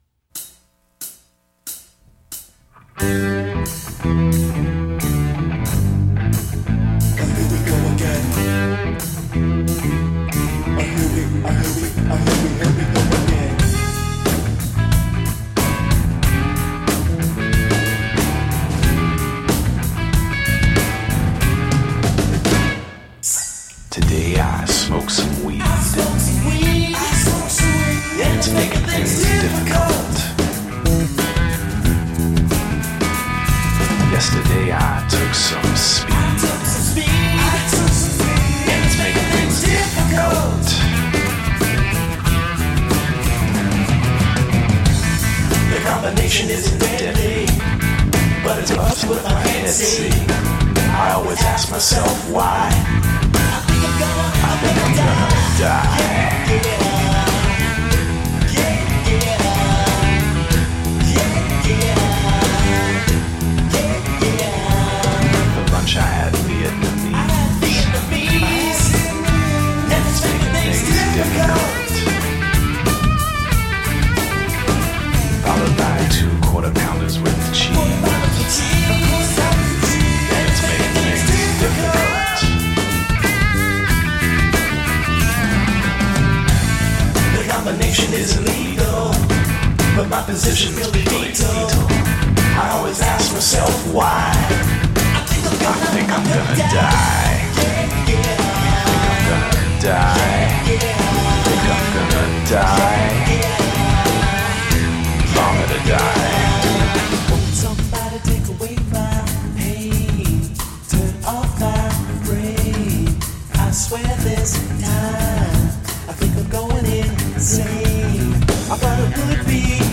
Smart-alecky, 'semi-conscious' hip-hop.
Tagged as: Hip Hop, Other